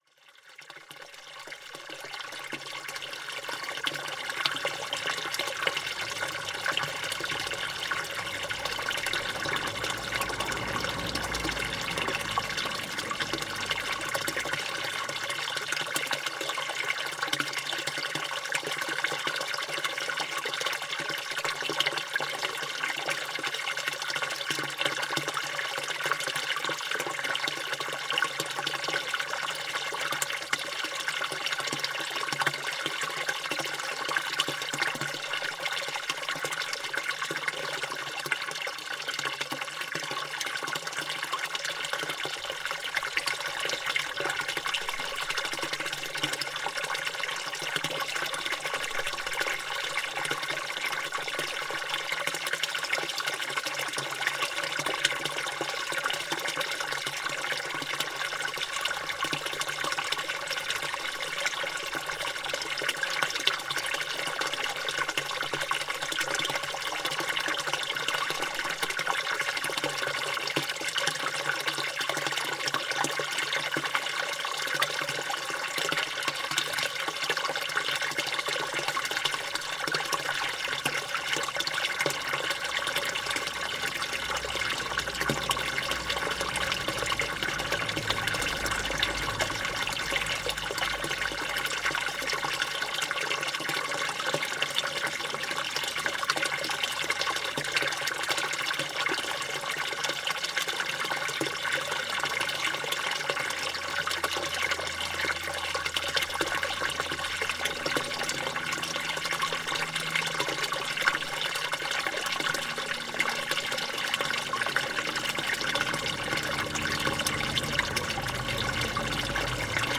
A água da fonte nova no largo junto à estrada é fria e recalcitra.
NODAR.00562 – Campo: Escoamento de água de fonte no largo da Fonte da Igreja em Campo de Madalena
Tipo de Registo: Som